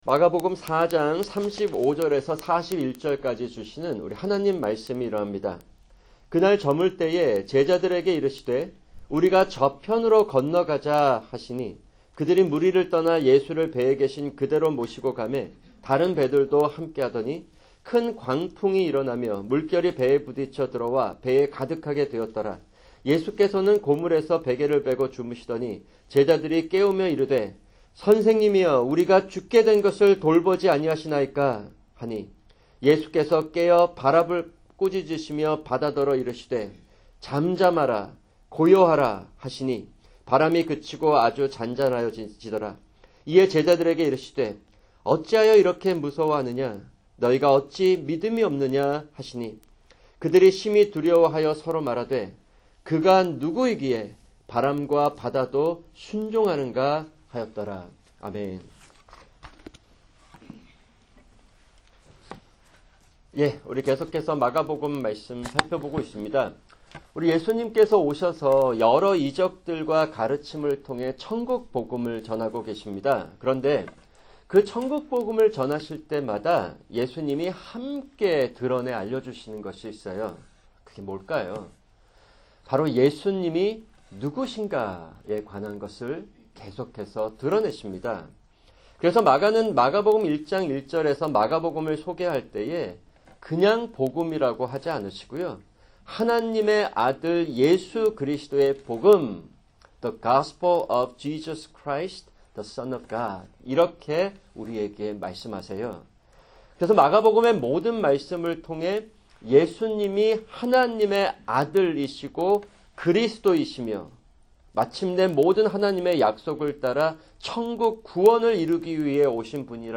[주일 설교] 마가복음(22) 4:35-41